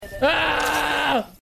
mikbattlecry.mp3